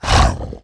role5_pain1.wav